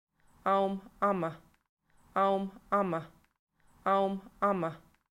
The recordings were gained from native speakers from the individual areas as part of the project Samples of Spoken Irish.
[listen] au